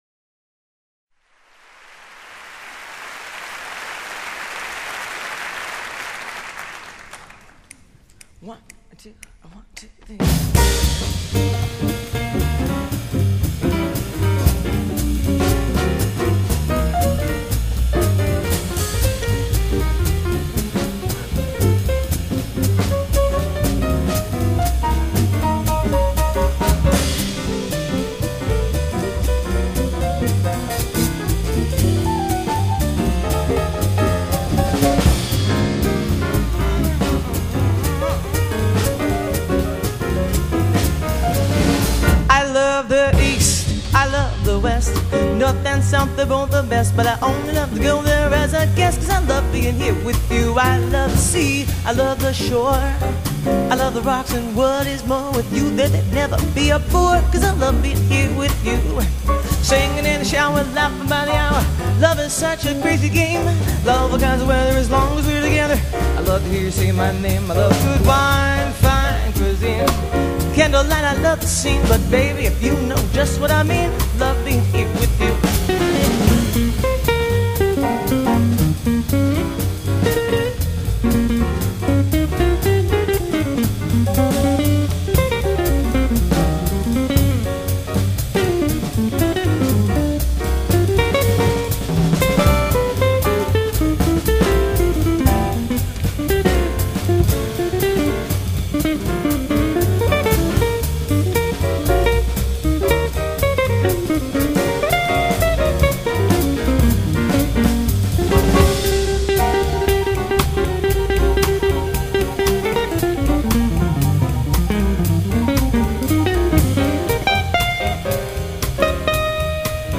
音乐类型：爵士乐
＊前美国总统柯林顿最爱爵士女歌手 首次现场录音专辑